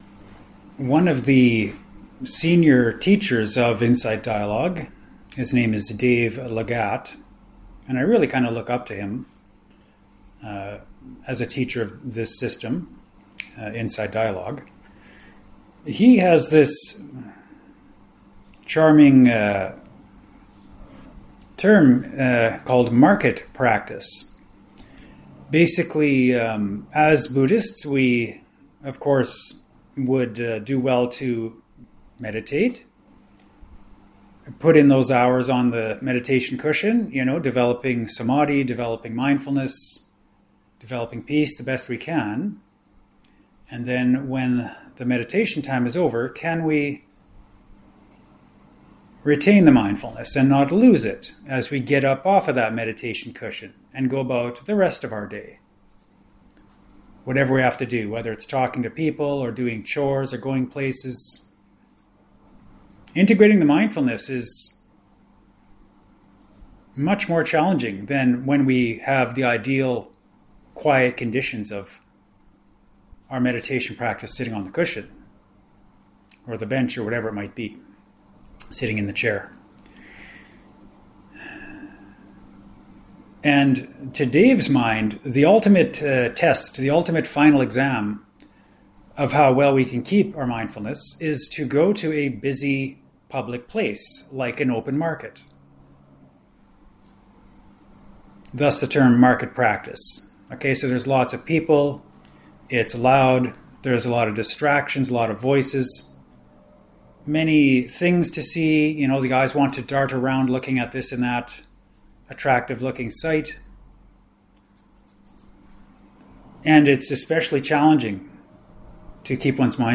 Here is a 17-min recorded audio-only Dhamma Talk, recorded while visiting BGF, Kuala Lumpur - downloadable 12MB .mp3 (Tip: tap and hold to “Download link”, or right click to “Save Link As…“).